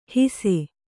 ♪ hise